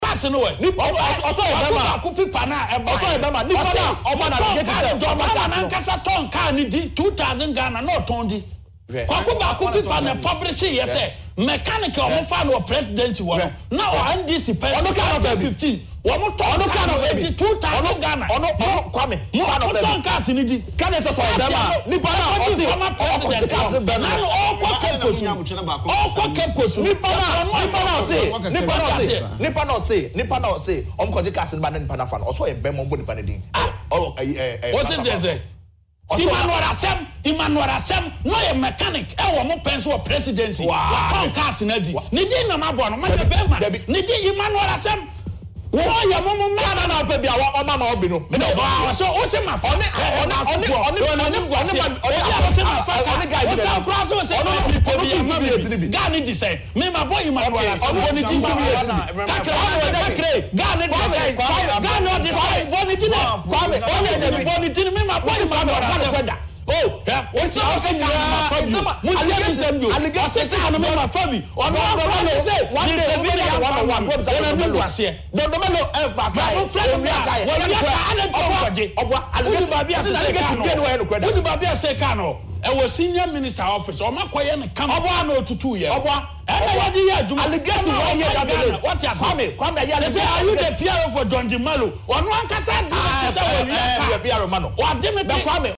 I am proud it is being used for official duties after we chased that young man the vehicle he acquired illegally,” he said on Accra based UTV.